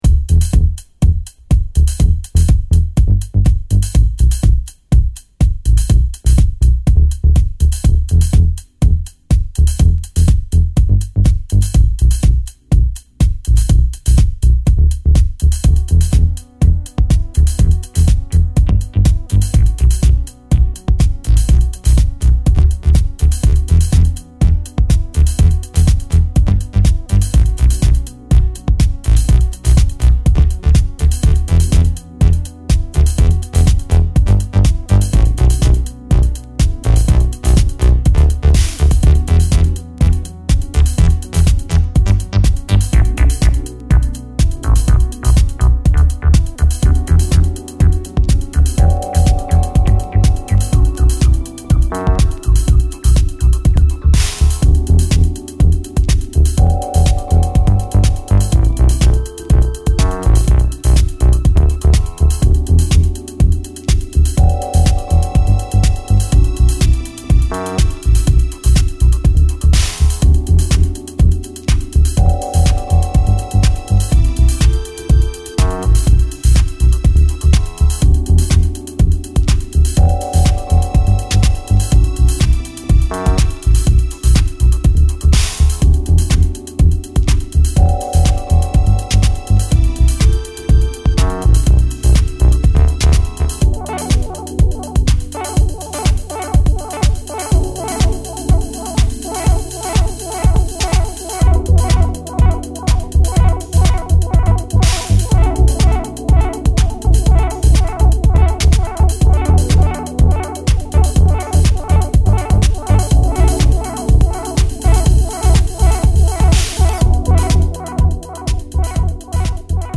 Balearic , Beatdown , Cosmic , House